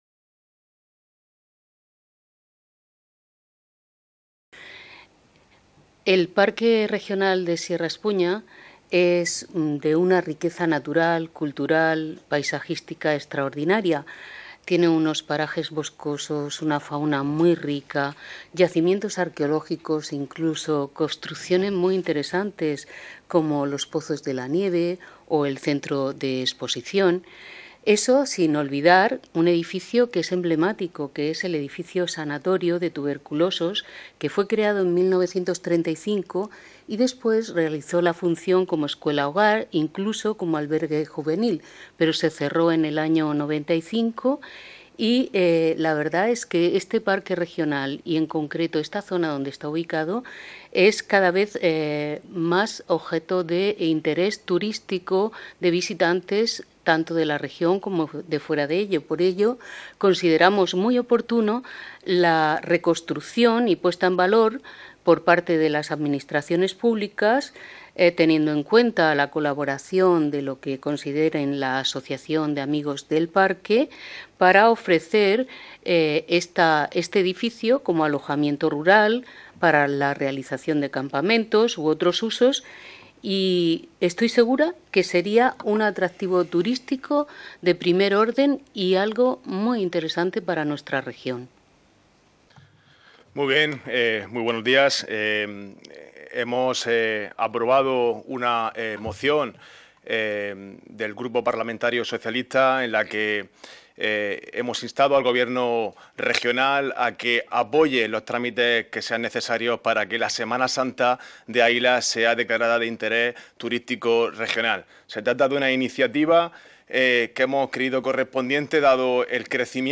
Ruedas de prensa posteriores a la Comisión de Industria, Trabajo, Comercio y Turismo
• Grupo Parlamentario Popular
• Grupo Parlamentario Socialista